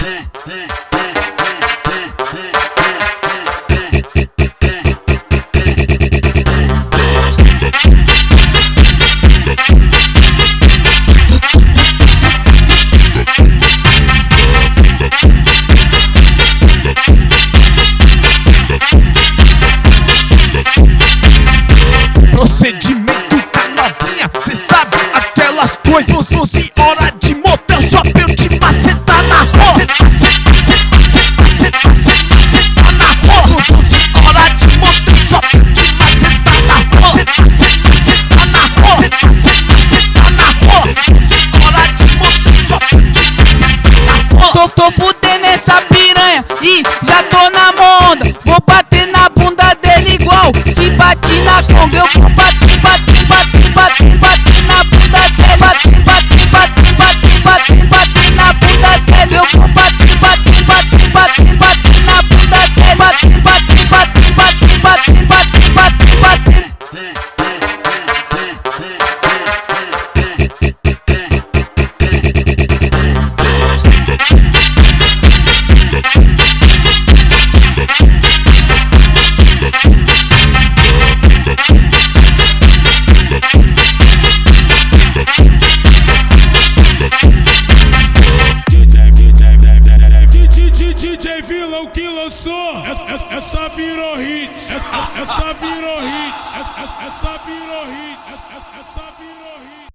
MEMORIES DE JAPAN (Phonk)